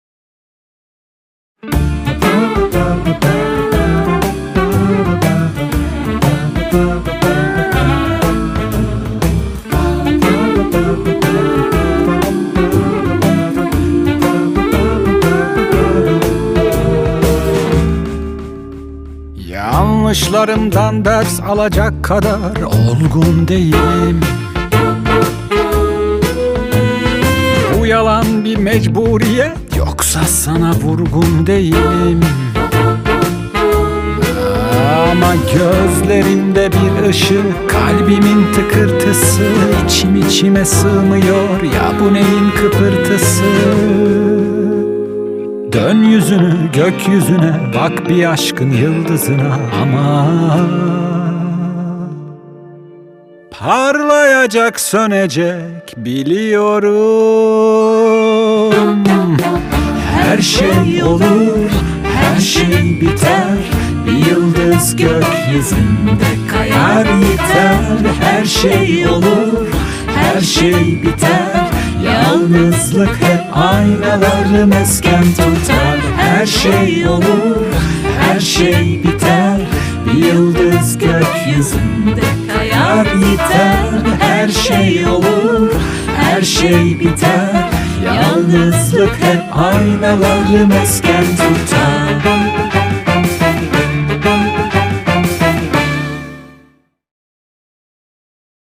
dizi müziği, neşeli eğlenceli enerjik şarkı.